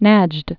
(năjd)